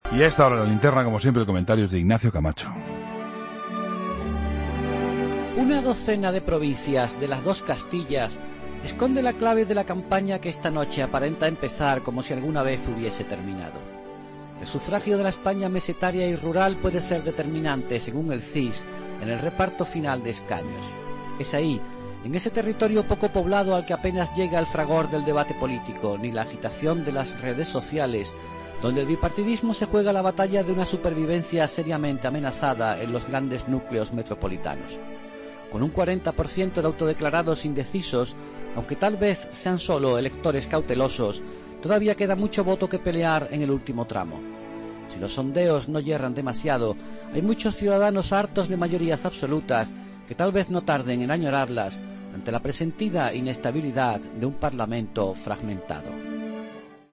AUDIO: Ignacio Camacho dedica su comentario a los resultados de la última encuesta del CIS